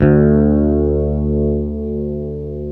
Index of /90_sSampleCDs/Roland L-CDX-01/BS _Jazz Bass/BS _Warm Jazz